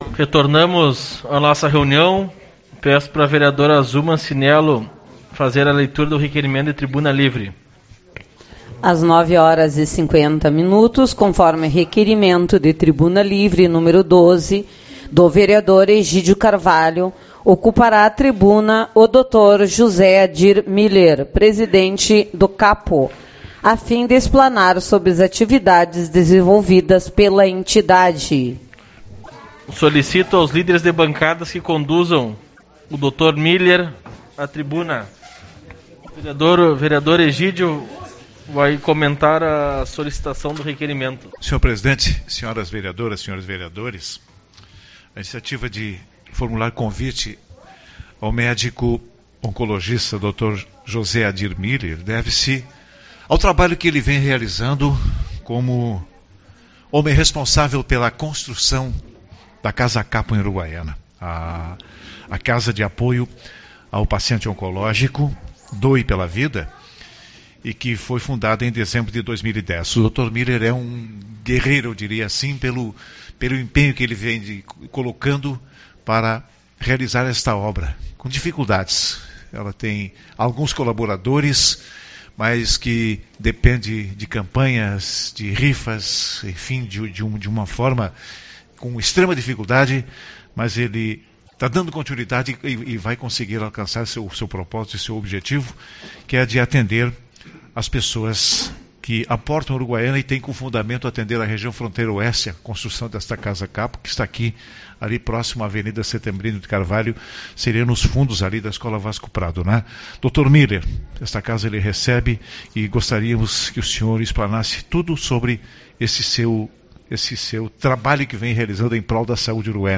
13/05 - Reunião Ordinária